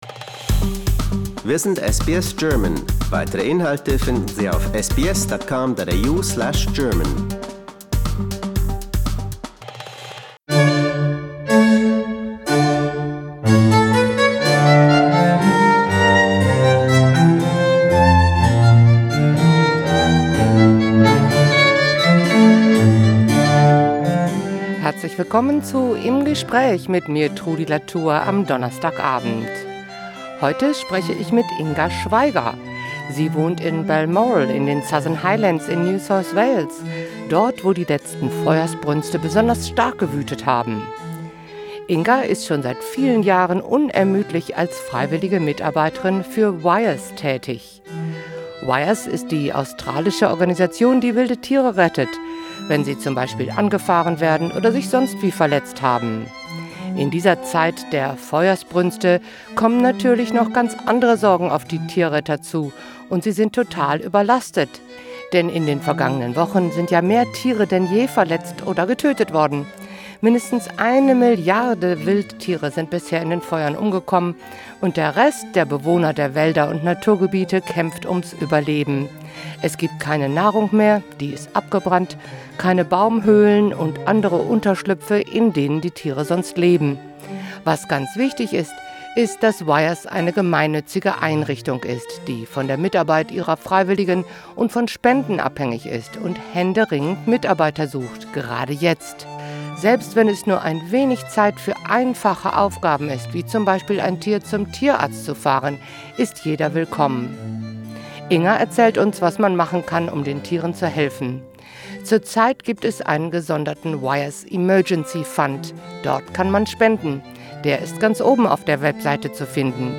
Im Gespräch: Tierrettung in der Zeit der Buschfeuer